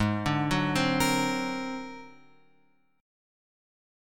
G# 9th Flat 5th